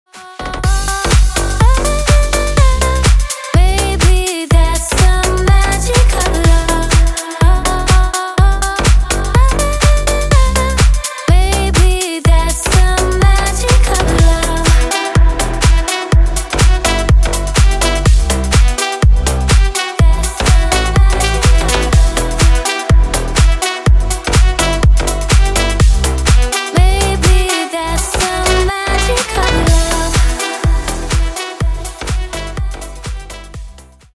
Клубные Рингтоны
Рингтоны Ремиксы » # Танцевальные Рингтоны